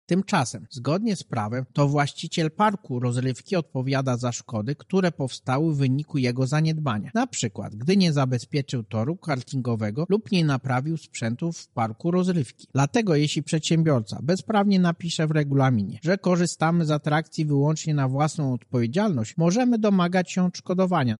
– Takie postanowienia są niezgodne z prawem. – mówi Marek Niechciał, prezes UOKiKu: